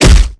melee_pound.wav